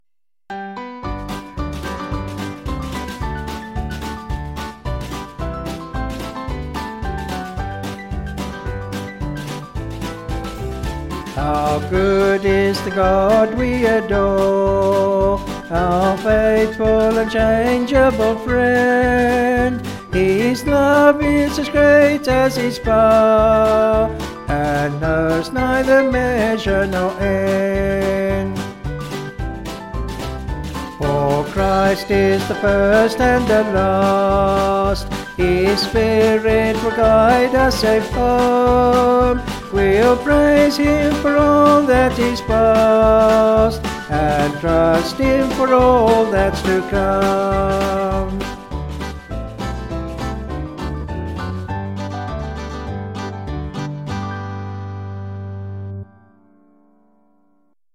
Vocals and Band   276.4kb Sung Lyrics